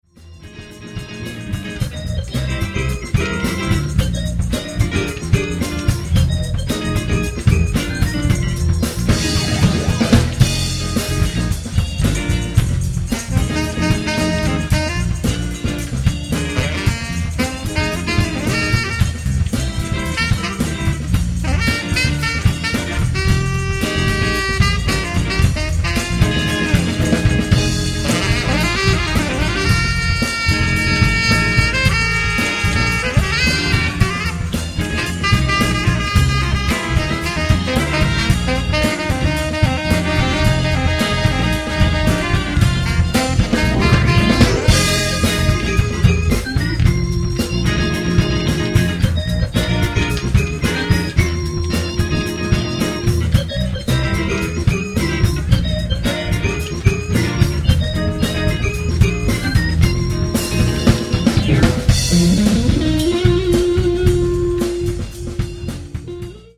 Funk/ Lounge Band